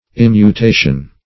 Search Result for " immutation" : The Collaborative International Dictionary of English v.0.48: Immutation \Im"mu*ta"tion\, n. [L. immutatio, from immutare, immutatum, to change.